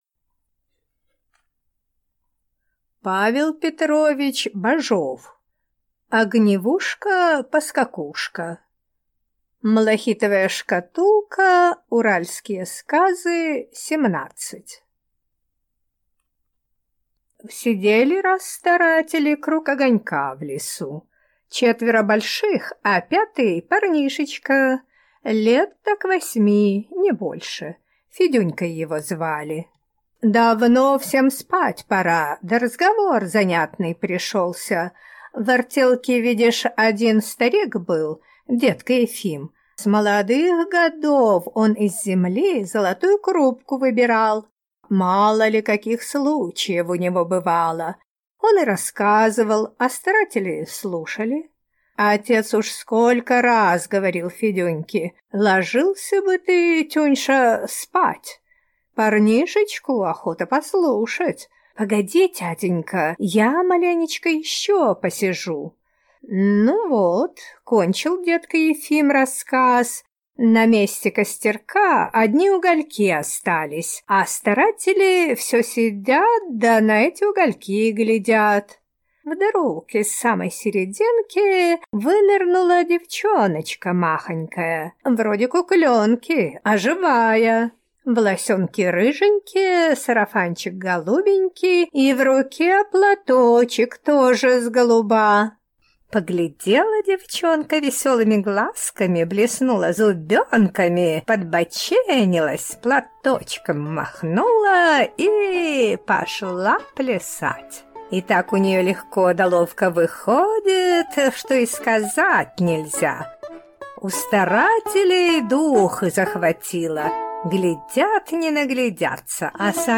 Аудиокнига Огневушка-Поскакушка | Библиотека аудиокниг